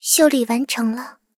追猎者修理完成提醒语音.OGG